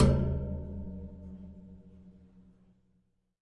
电风扇金属烤架样品 " 电风扇烤架 打2
描述：电风扇作为打击乐器。击打和刮擦电风扇的金属格栅可以发出美妙的声音。
Tag: 金属的 混响 电动风扇 样品